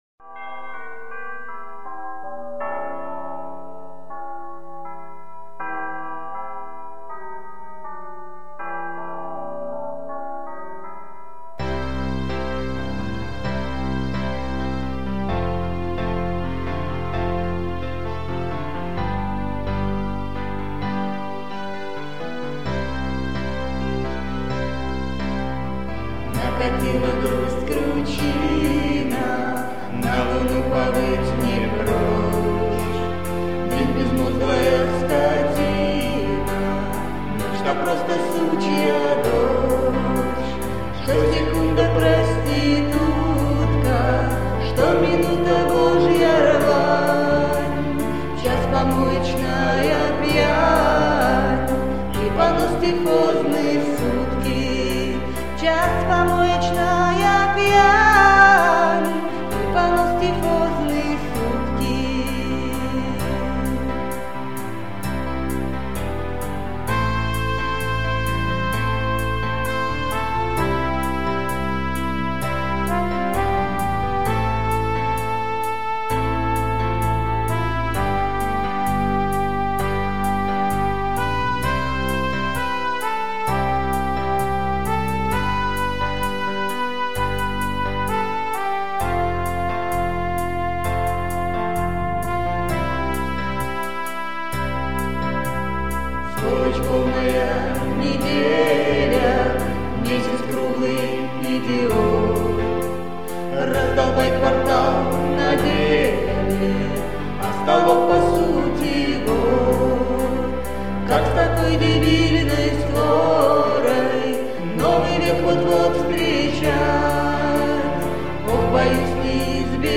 Записей у группы гораздо больше, хотя в основном они (альбом "Засада" - исключение) так и остаются в черновом варианте.
Б. Демо-записи для других исполнителей